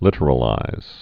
(lĭtər-ə-līz)